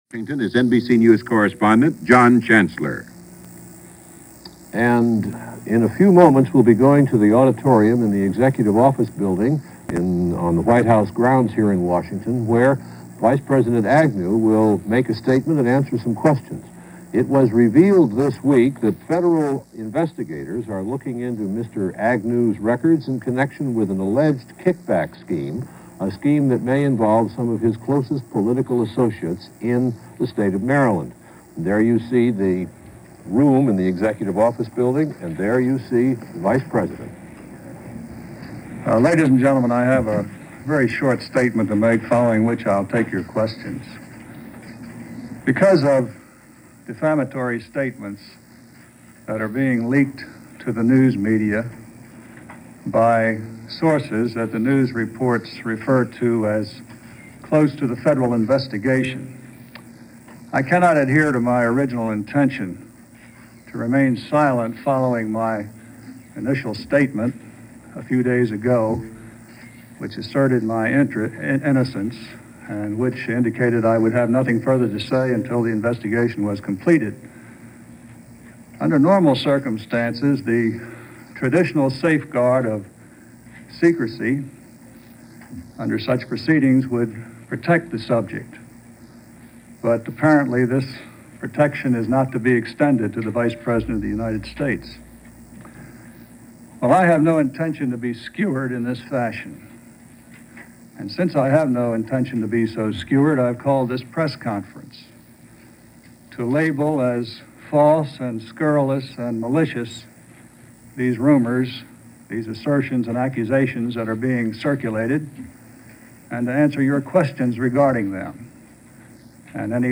" - August 8, 1973 - Press conference given by vice-President Spiro Agnew in Washington.
Spioro-Agnew-News-Conference.-August-8-1973.mp3